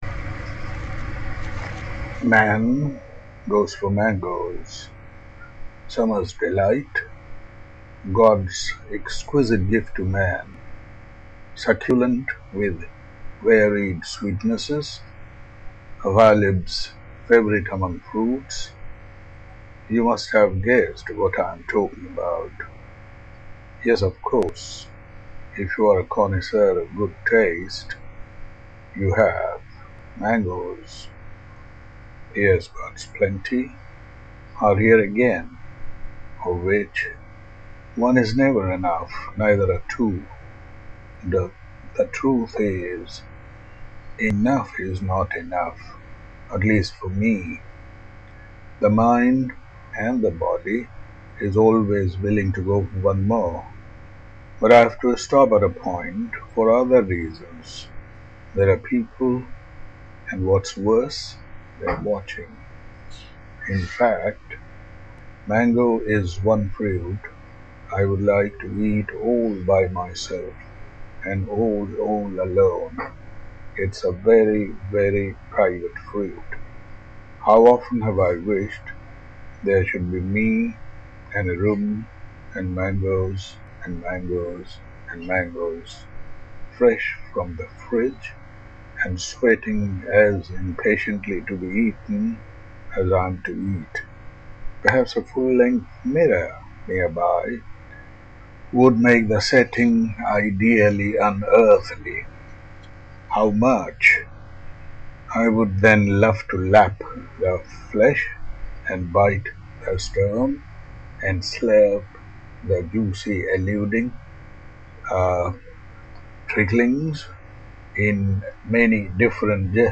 A voice recording of a middle from book NAVEL IDEAS just published.